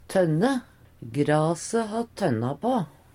tønne - Numedalsmål (en-US)